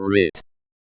ɹɪt/ is a formal written order commanding someone to do some particular thing… or else.
writ.opus